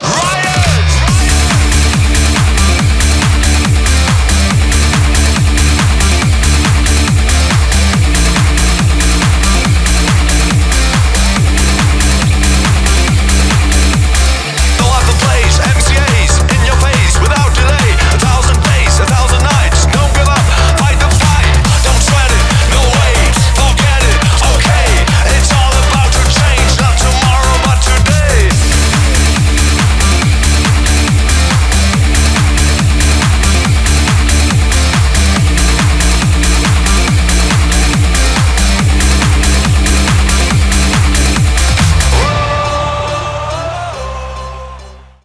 • Качество: 320, Stereo
поп
dance
electro
Немецкий музыкальный коллектив